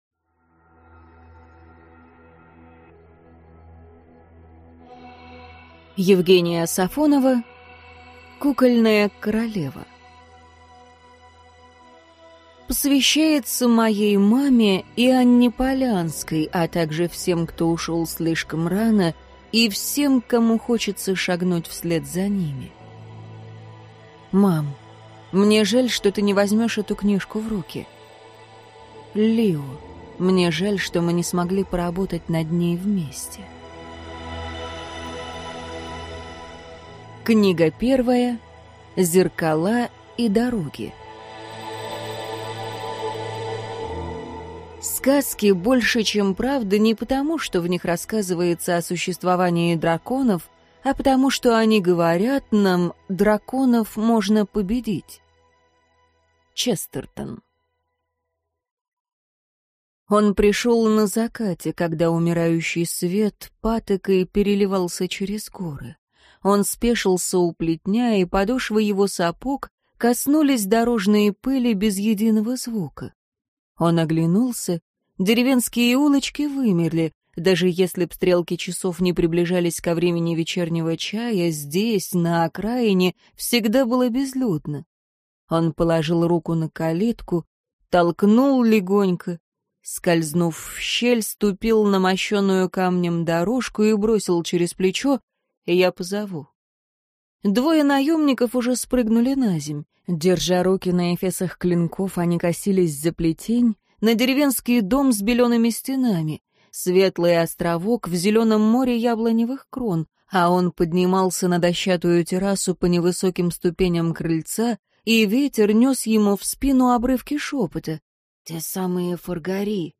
Аудиокнига Кукольная королева | Библиотека аудиокниг